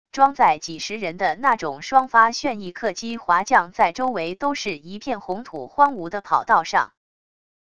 装载几十人的那种双发旋翼客机滑降在周围都是一片红土荒芜的跑道上wav音频